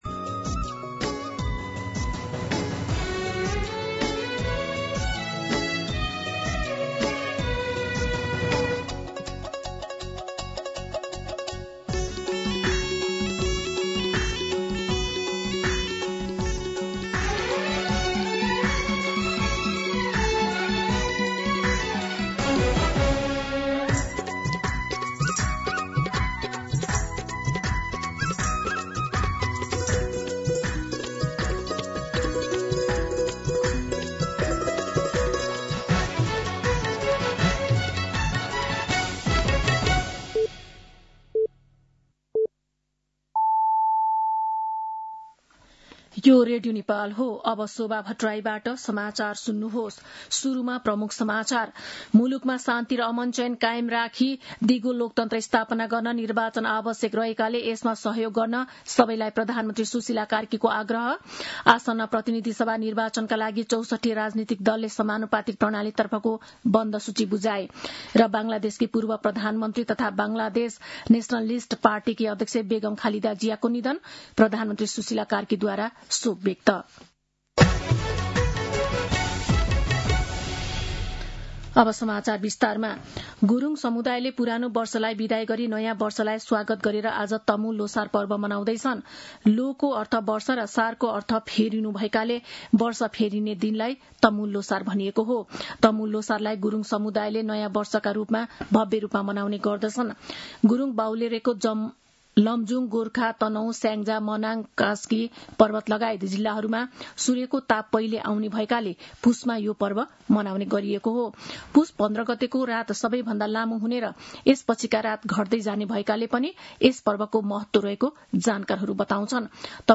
दिउँसो ३ बजेको नेपाली समाचार : १५ पुष , २०८२